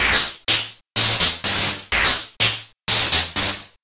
cch_synth_silk_125_Gm.wav